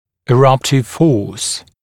[ɪ’rʌptɪv fɔːs][и’раптив фо:с]сила прорезывания